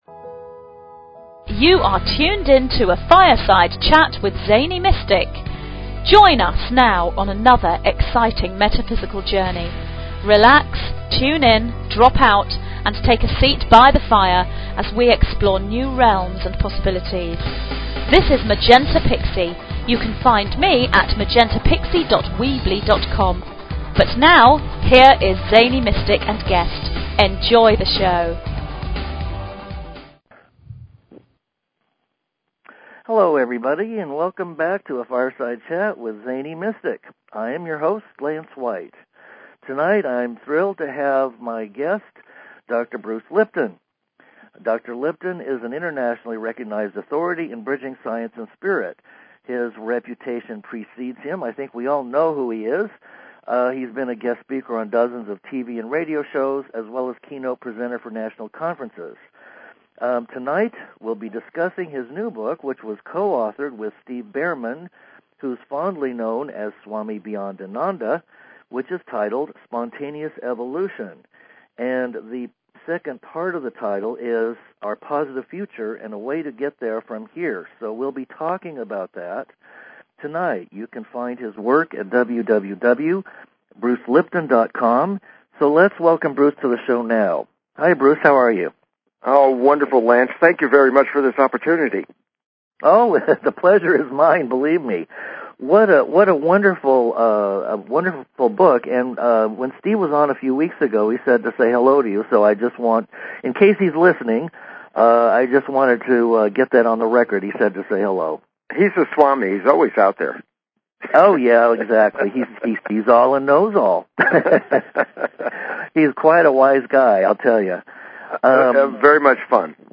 Talk Show Episode, Audio Podcast, A_Fireside_Chat and Guest, Dr. Bruce M. Lipton, Ph.D. on , show guests , about , categorized as
Guest, Dr. Bruce M. Lipton, Ph.D.